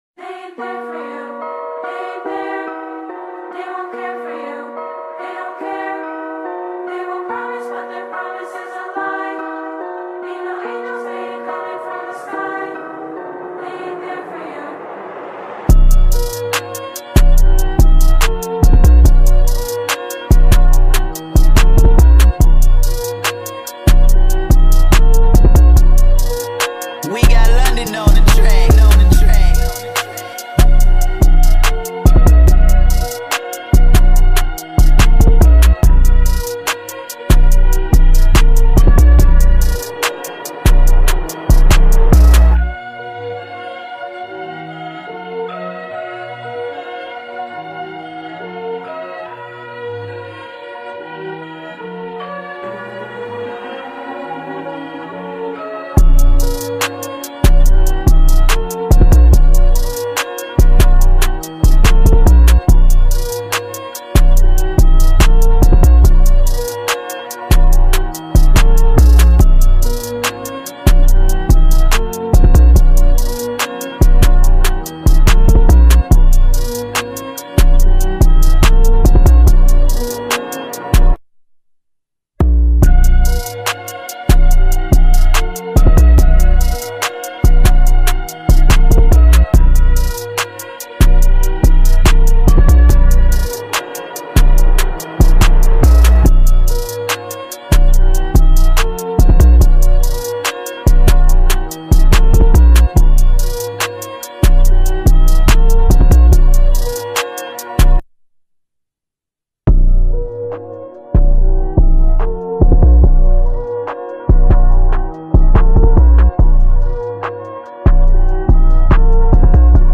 This is the instrumental for the new song.